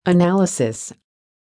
1. Now look at the words and learn how to pronounce them.
analysis.mp3